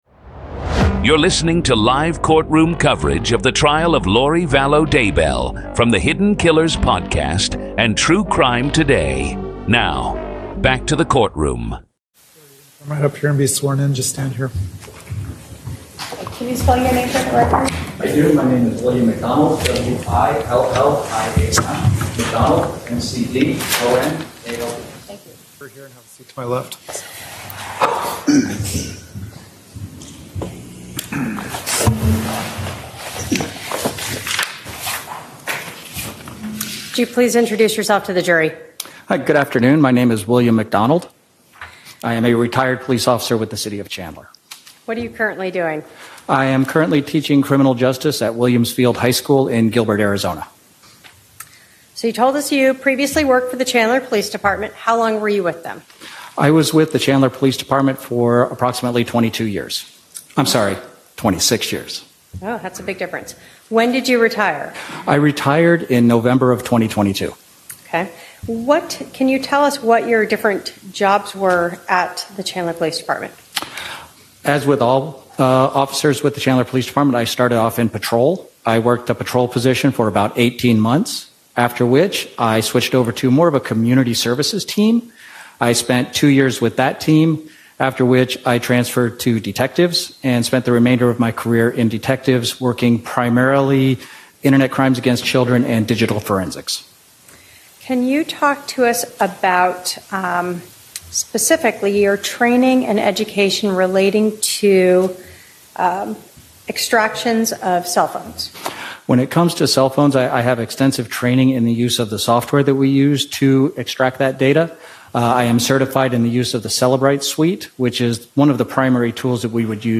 Today on Hidden Killers, we bring you the full, raw, unfiltered courtroom coverage from Day 4 of Lori Vallow Daybell’s murder trial in Arizona—exactly as it happened.
And through it all, you’ll hear Lori herself—calm, controlled, representing herself, cross-examining her own family, and showing little to no emotion as her beliefs, her behavior, and her potential motives are laid bare. This is the complete courtroom audio from April 10, 2025 —no edits, no filters, just the raw reality of one of the most bizarre and disturbing murder trials in modern history.